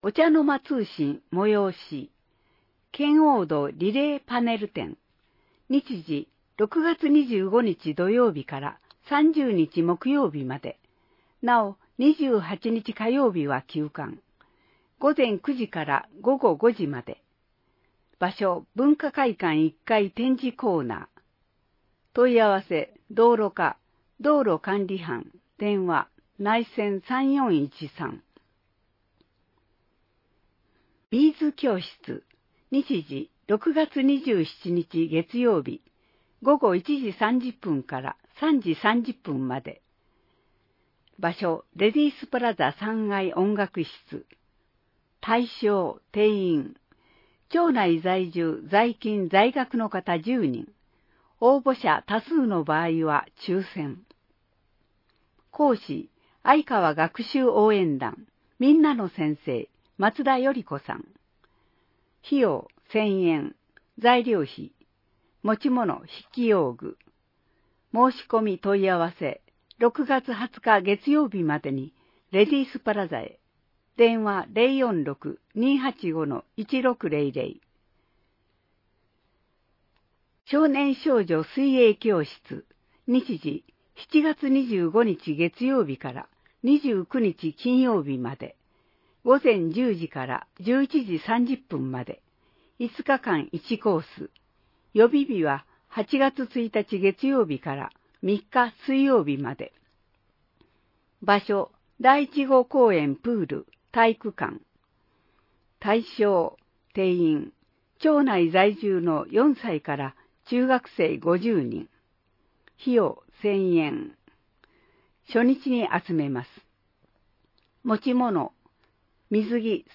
まちの助成制度」 (PDFファイル: 2.3MB) 保健ガイド (PDFファイル: 2.7MB) お茶の間通信 (PDFファイル: 2.1MB) あいかわカレンダー (PDFファイル: 1.3MB) 音声版「広報あいかわ」 音声版「広報あいかわ」は、「愛川町録音ボランティアグループ かえでの会」の皆さんが、視覚障がい者の方々のために「広報あいかわ」を録音したものです。